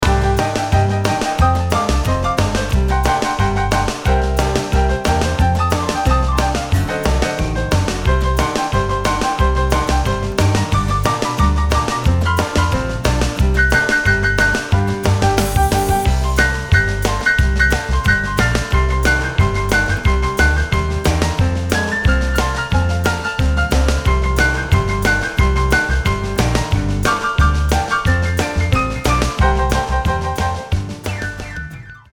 180 BPM
8 to the bar piano dominates this rockabilly frenzy.